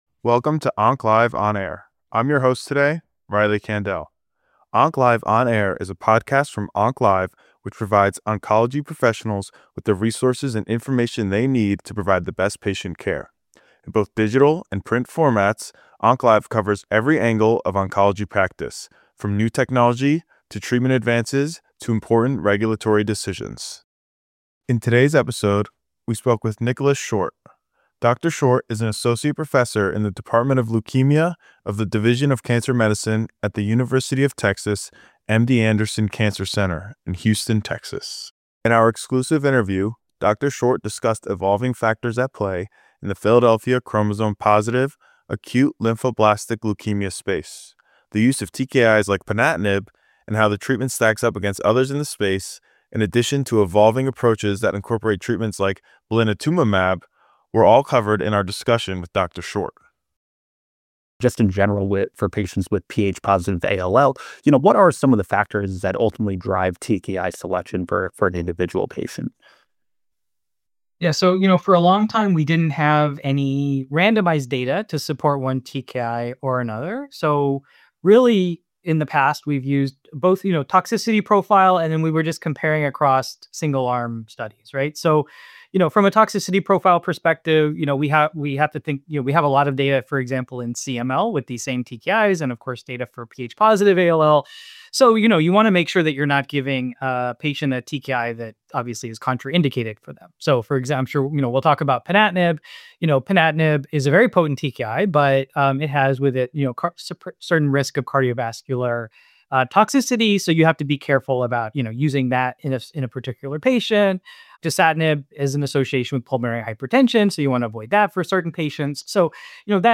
In our exclusive interview